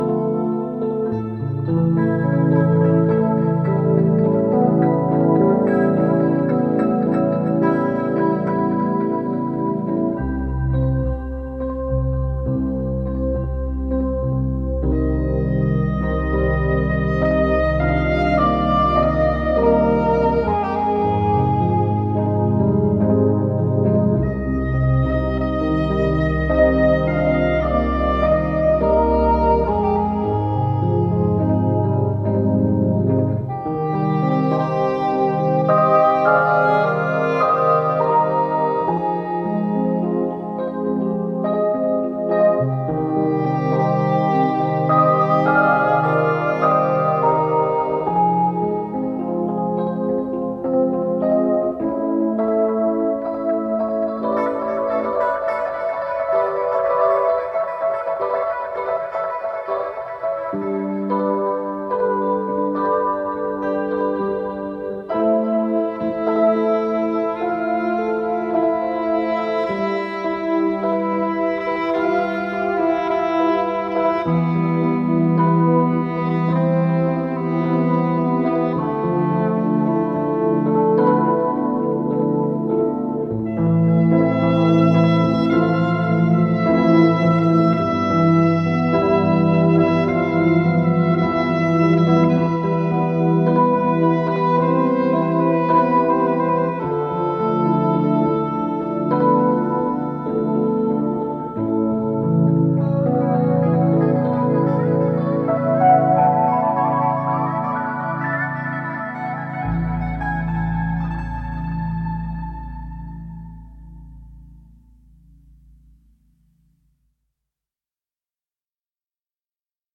peculiar pop (muita instrumental)